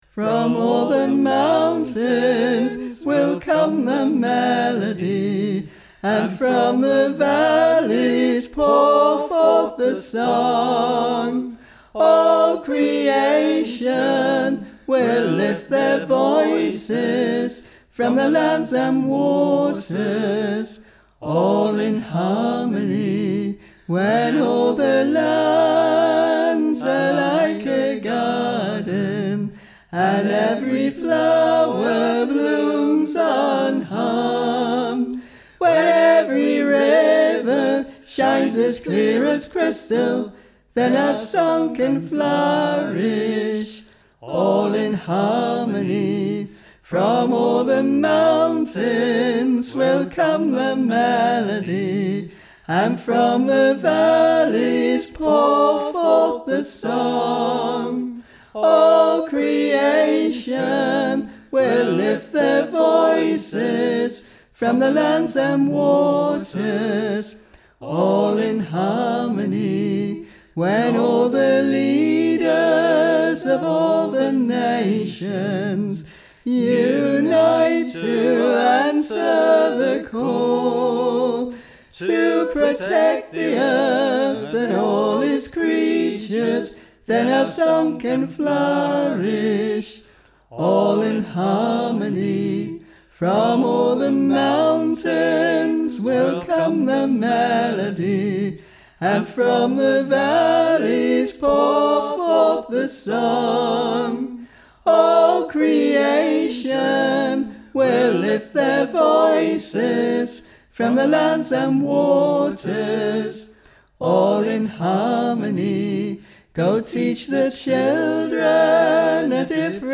Each of the others brings one of the parts to prominence (though you can hear the other parts in the background to give context).
Tenor:
All_in_Harmony_tenor.mp3